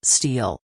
steal kelimesinin anlamı, resimli anlatımı ve sesli okunuşu